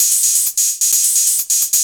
HIHAT1.mp3